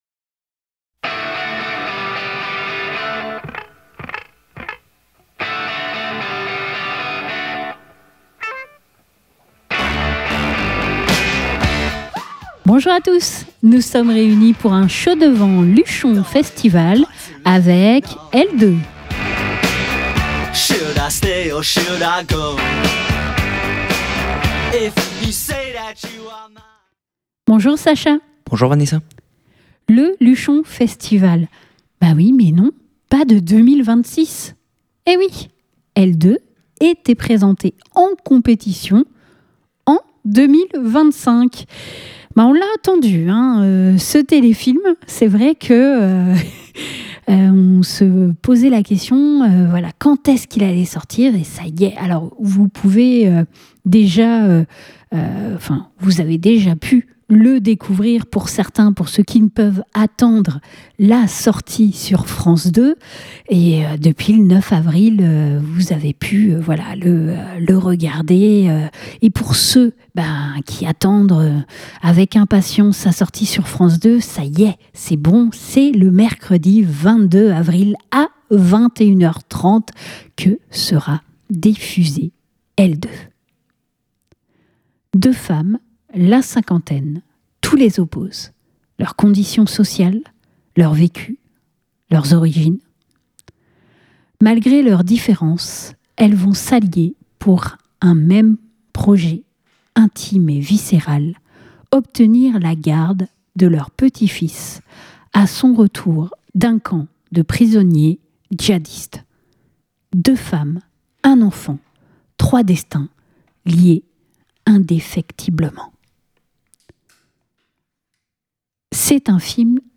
22 avril 2026 Écouter le podcast Télécharger le podcast Lors du Luchon Festival 2025 nous avons pu rencontrer l'équipe du film "Elles deux" qui était en compétition officielle.